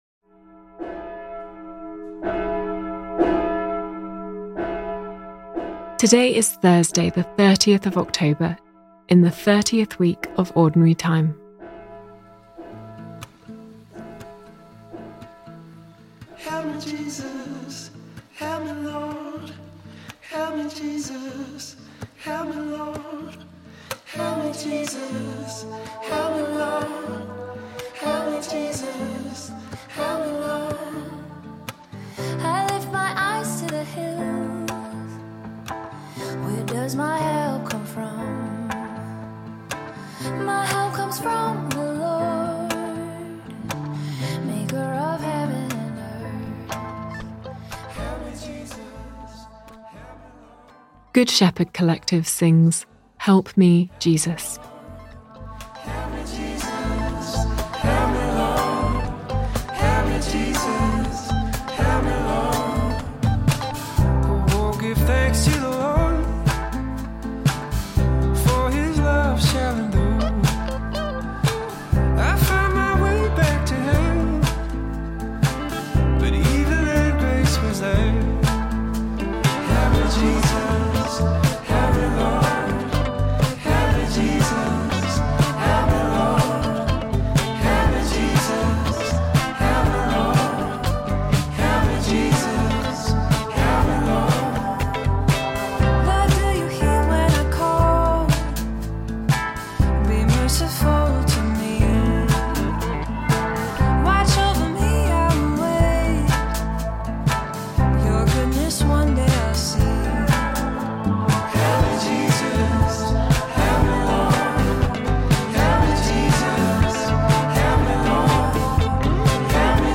Good Shepherd Collective sings, ‘Help Me Jesus’. Today’s reading is from Saint Paul’s Letter to the Romans.